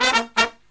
Index of /90_sSampleCDs/USB Soundscan vol.29 - Killer Brass Riffs [AKAI] 1CD/Partition D/03-133PERFS1